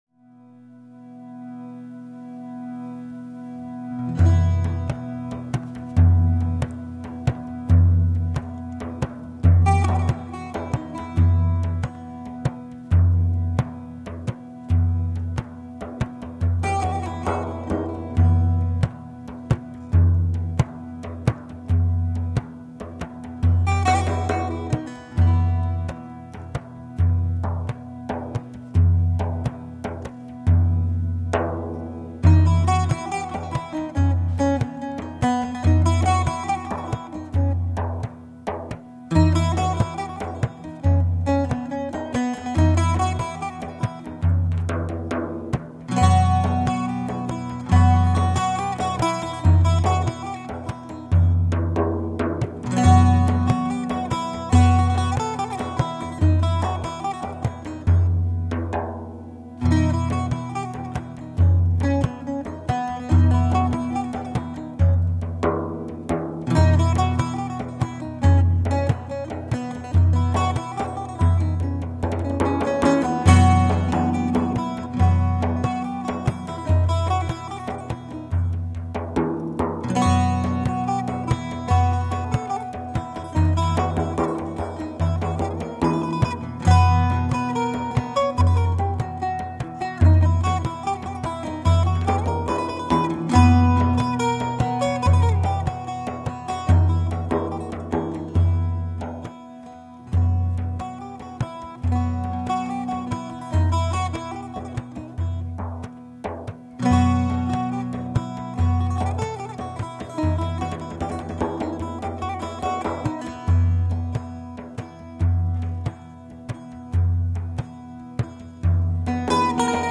Live at Hillsboro-Deering High School 3/20/08